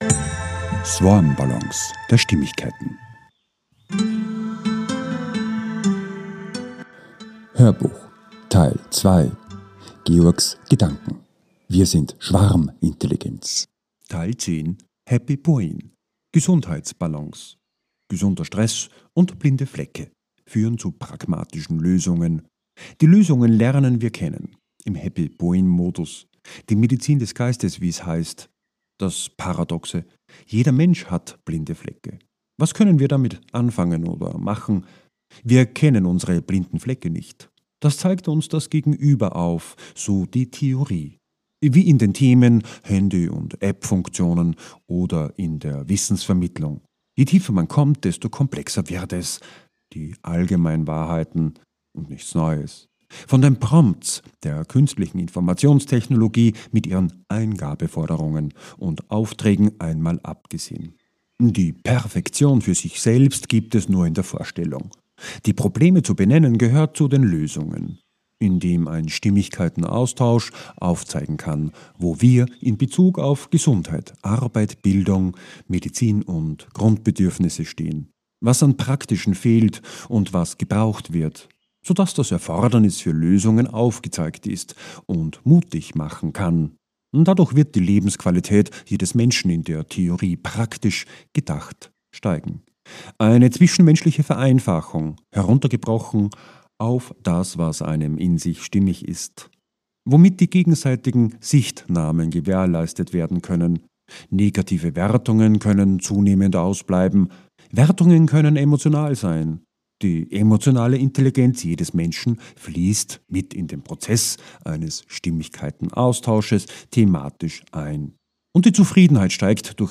HÖRBUCH TEIL 2 - 10 - WIR SIND SCHWARMINTELLIGENZ 2 - HAPPY BRAIN - GESUNDHEIT ~ SwarmBallons A-Z der Stimmigkeit Podcast